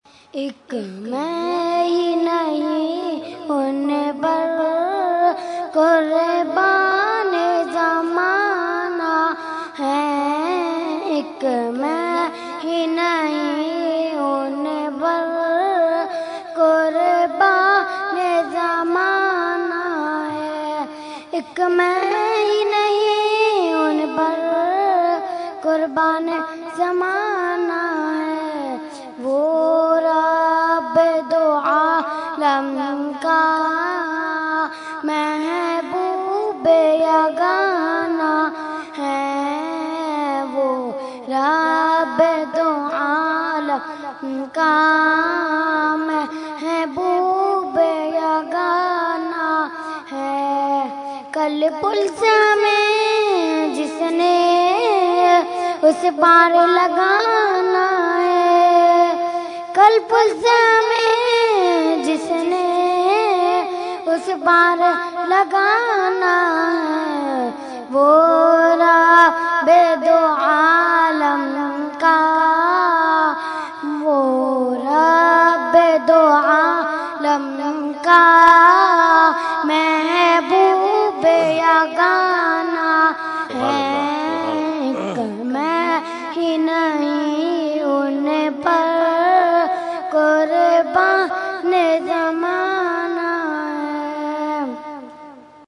Category : Naat | Language : UrduEvent : Urs Ashraful Mashaikh 2015